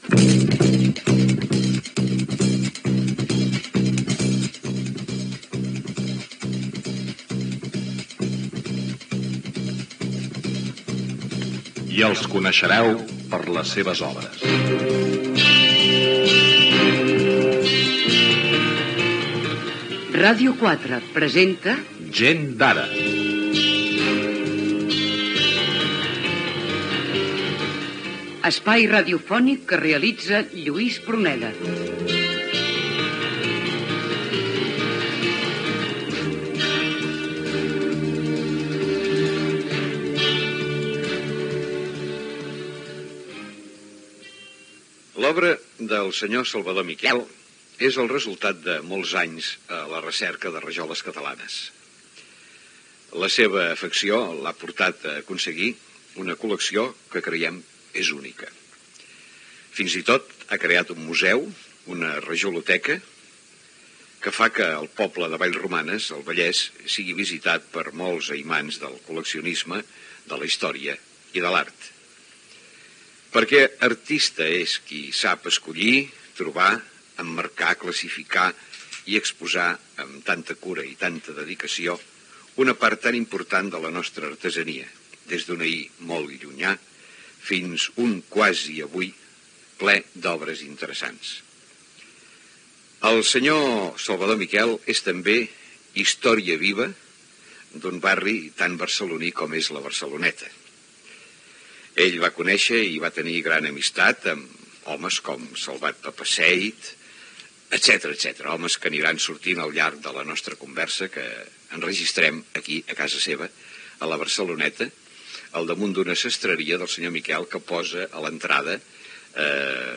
Careta
Gènere radiofònic Entreteniment